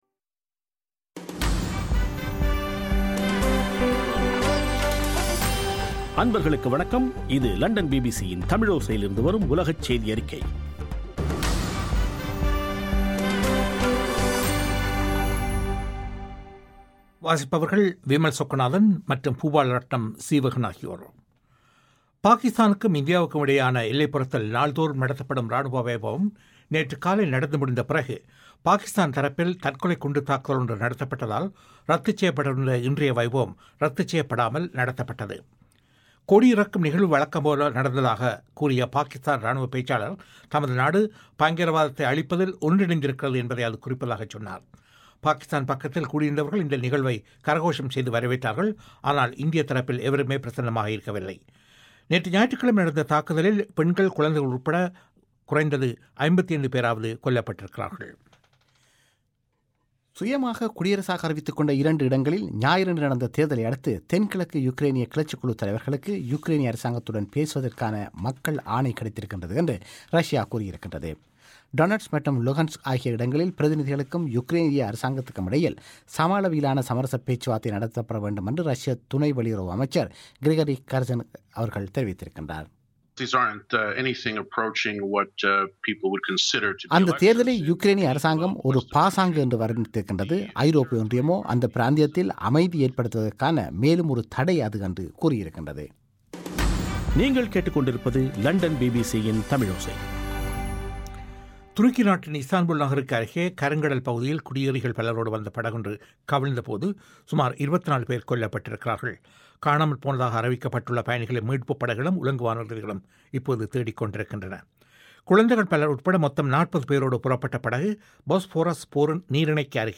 நவம்பர் 3, 2014 பிபிசி தமிழோசையின் உலகச் செய்திகள்